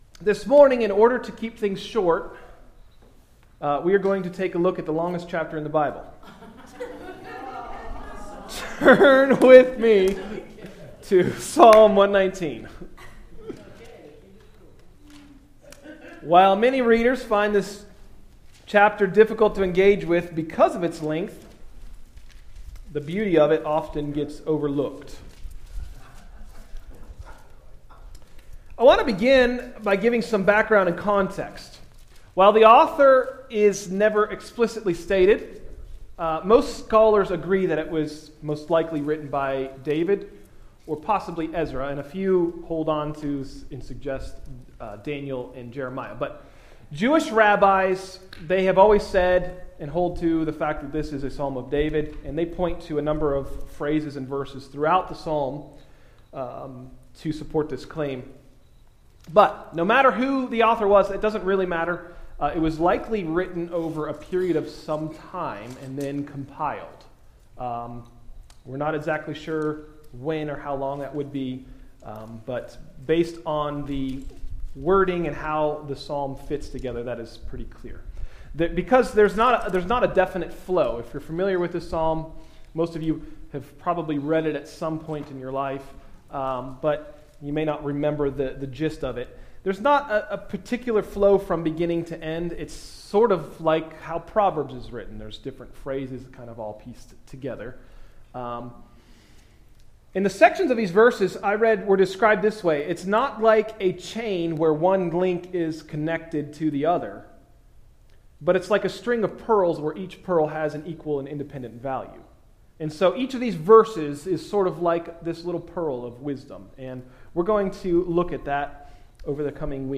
Message: “Psalm 119: An Introduction” – Tried Stone Christian Center